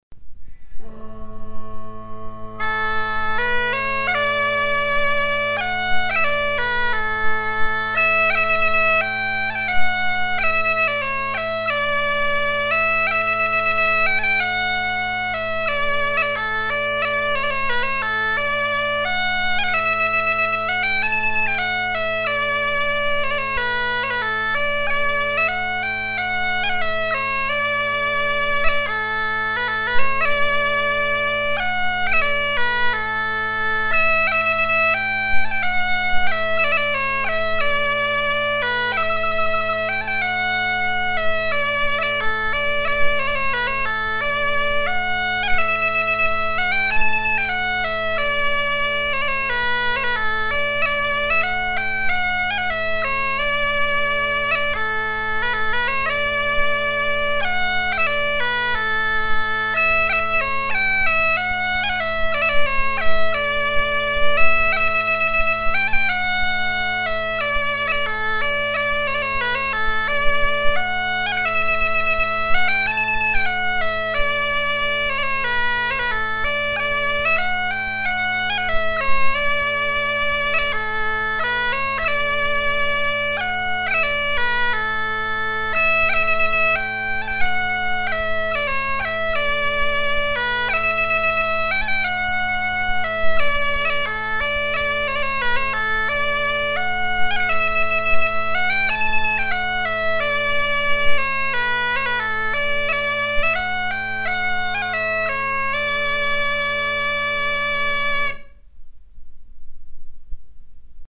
Version recueillie vers 1980
Chants de mariniers
Pièce musicale éditée